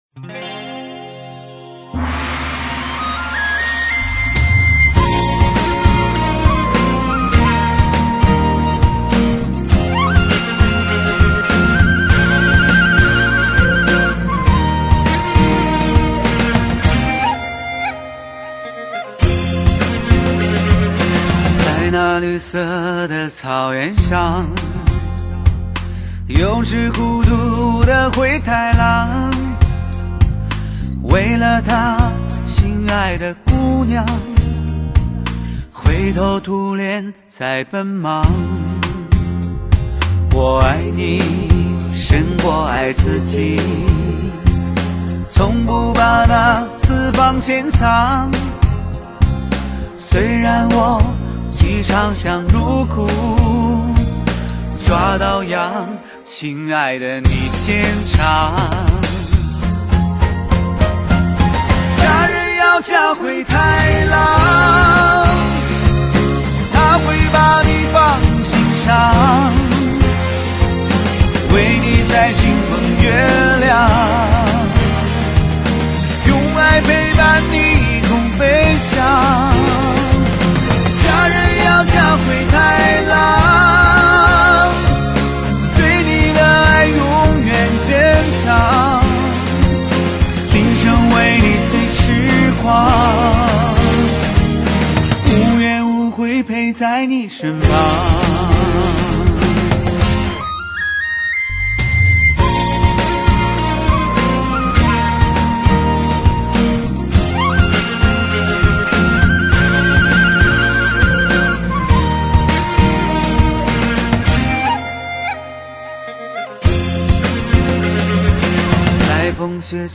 歌曲旋律温暖，朗朗上口。
粗犷中带着柔情的声音，温暖的氛围、温馨的感动，那么自然，那么亲切。